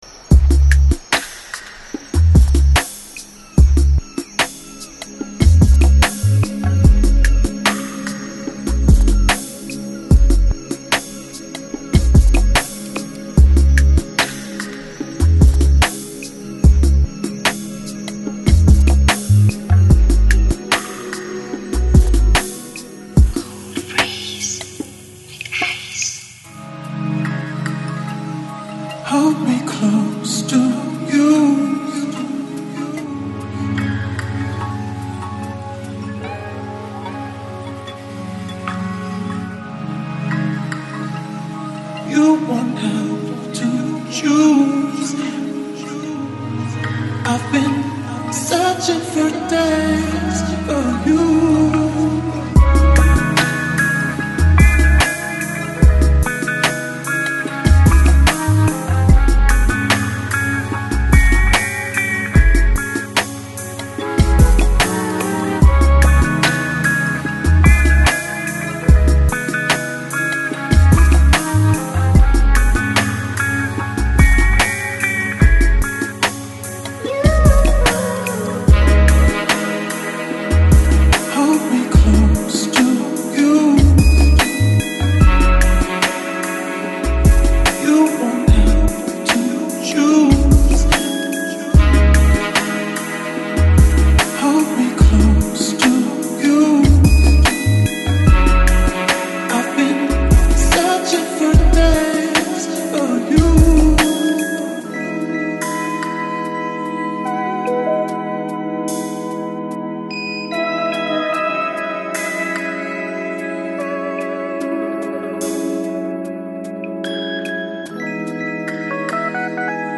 Жанр: Lounge Future Jazz Downtempo